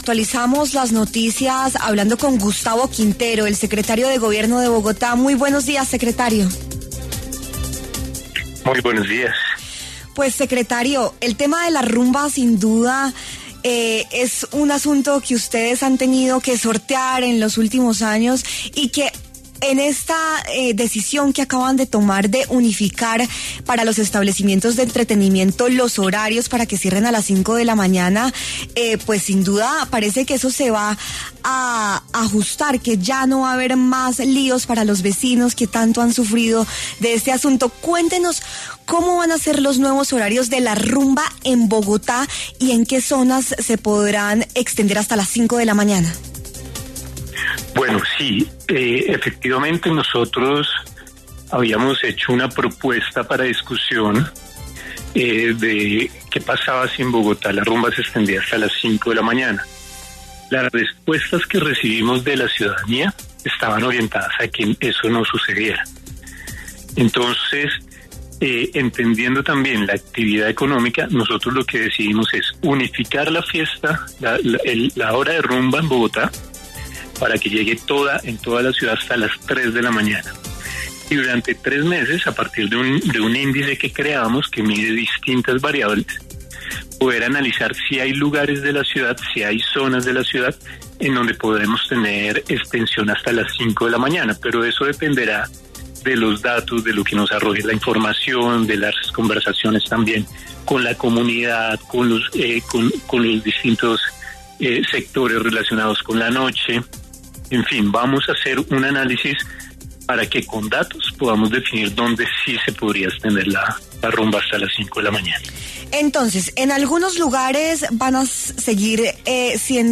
Gustavo Quintero, secretario de Gobierno de Bogotá, pasó por los micrófonos de W Fin de Semana para hablar sobre los horarios de rumba en la capital del país, luego de que se anunciara que se unificarán para que los establecimientos de entretenimiento cierren a las 5 de la mañana, pero en zonas determinadas, ya que en los lugares que no estén incluidos, la fiesta irá hasta las 3 a.m.